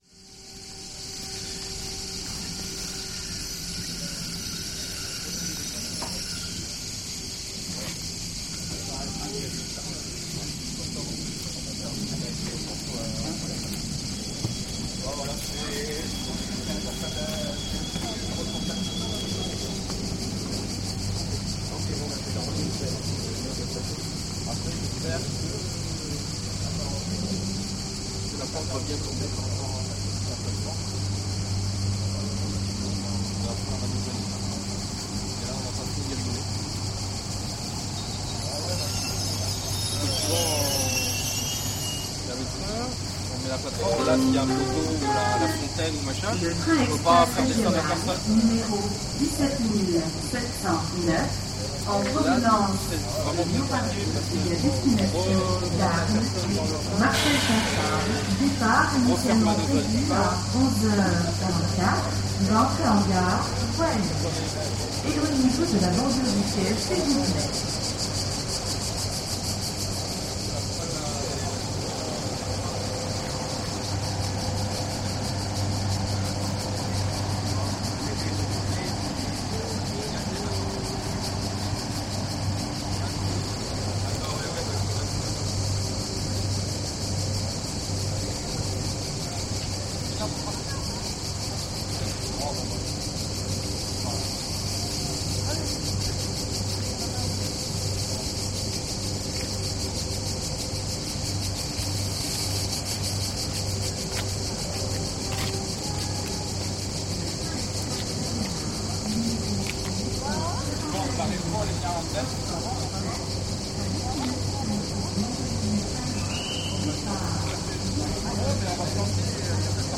Avignon train station